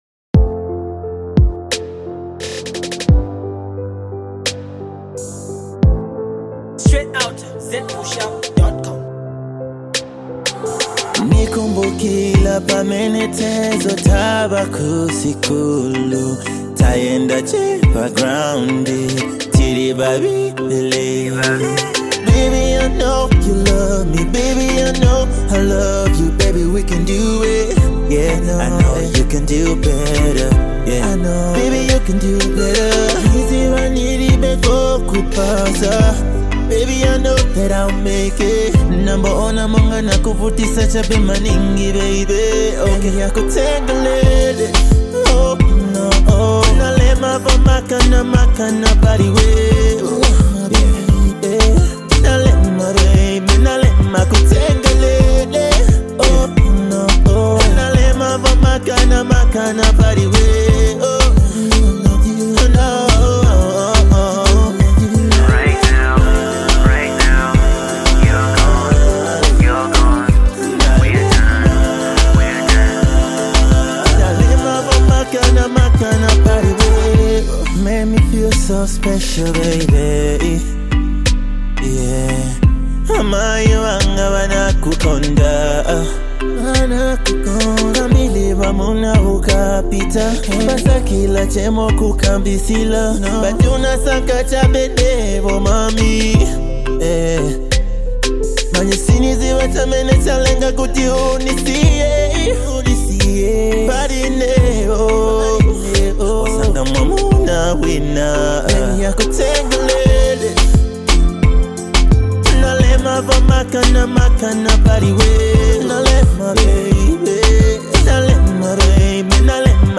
RnB jam